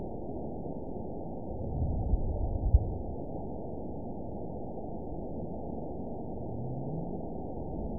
event 917162 date 03/22/23 time 14:37:54 GMT (2 years, 1 month ago) score 9.59 location TSS-AB01 detected by nrw target species NRW annotations +NRW Spectrogram: Frequency (kHz) vs. Time (s) audio not available .wav